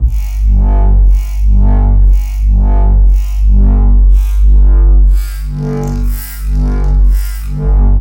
标签： 120 bpm Dubstep Loops Bass Wobble Loops 1.35 MB wav Key : Unknown
声道立体声